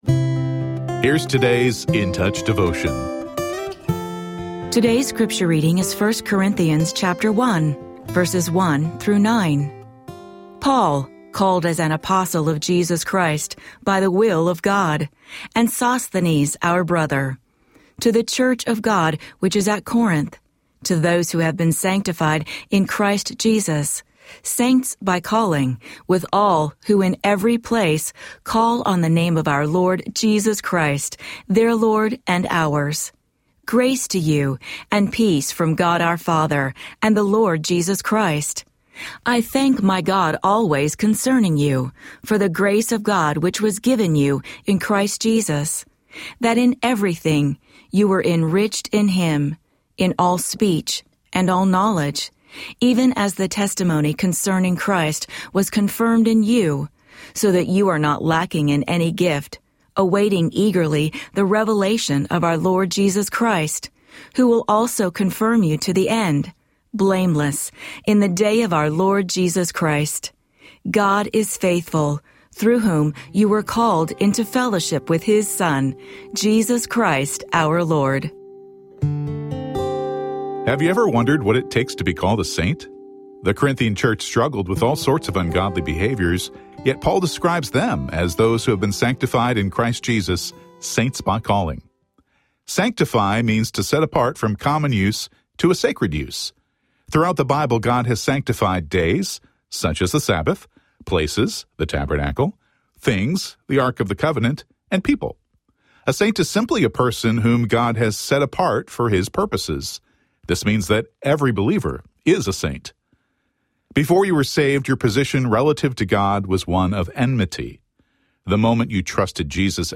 Daily audio devotional from Charles Stanley’s In Touch Ministries.